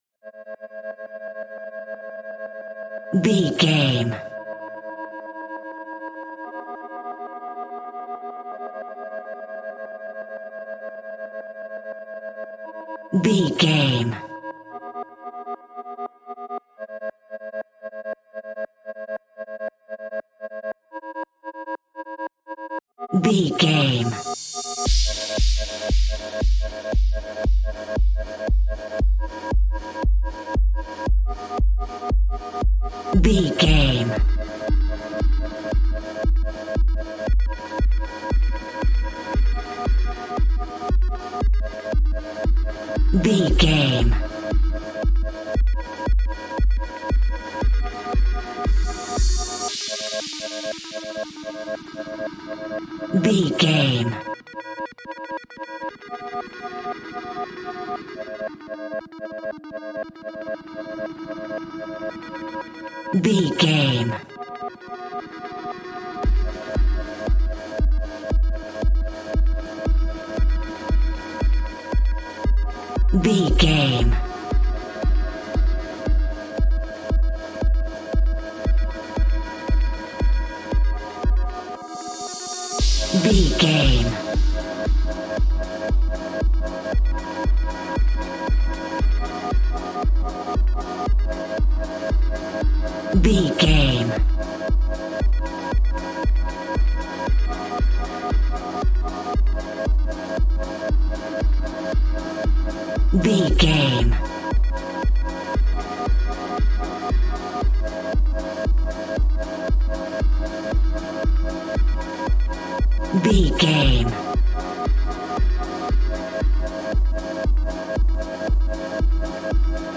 Aeolian/Minor
ethereal
dreamy
cheerful/happy
groovy
synthesiser
drum machine
house
electro dance
synth leads
synth bass
upbeat